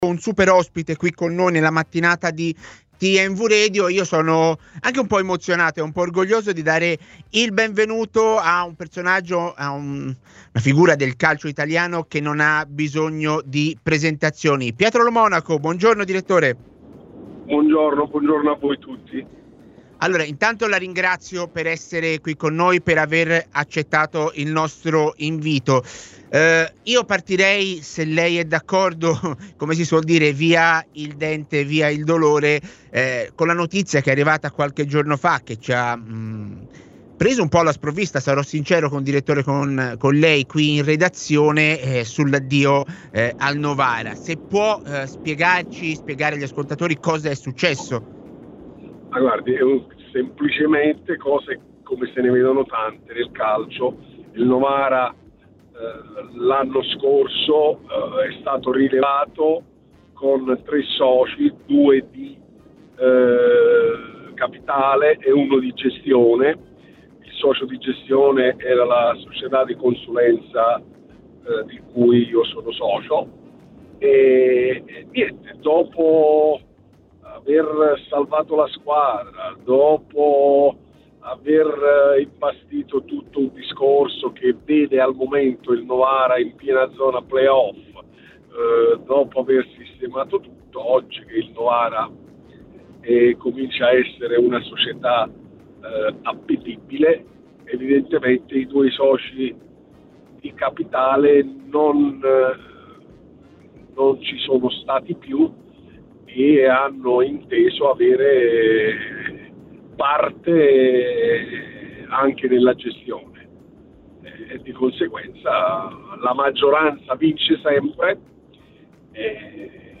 Sezione: Interviste